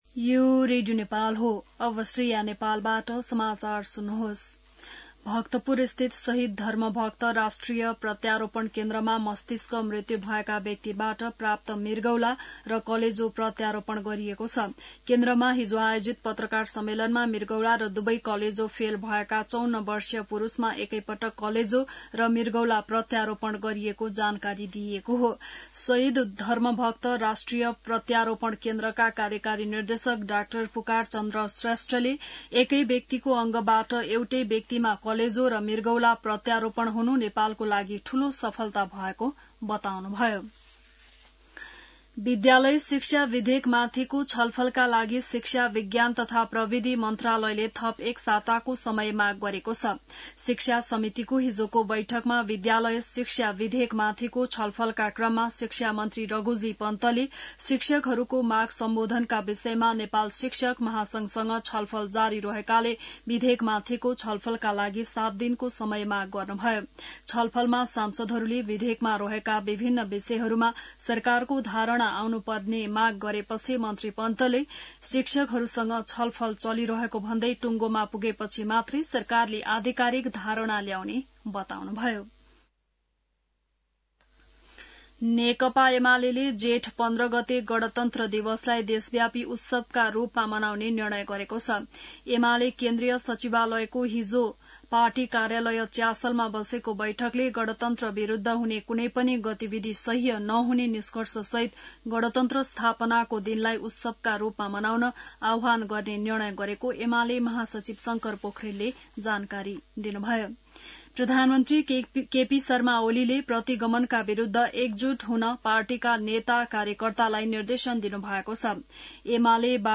बिहान १० बजेको नेपाली समाचार : १२ जेठ , २०८२